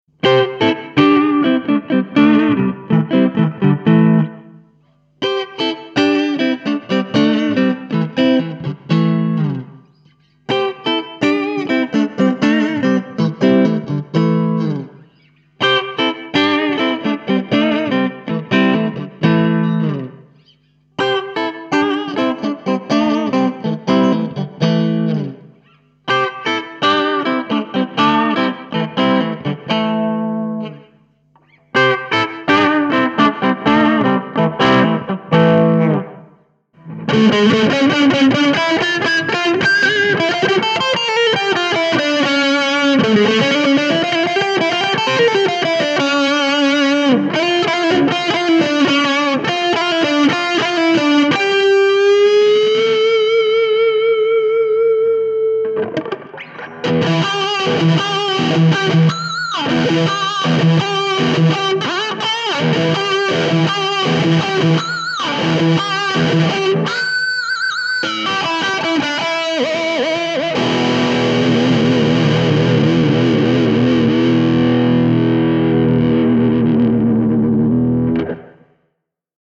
Cort X-6 VPR demo sounds 1,86 ��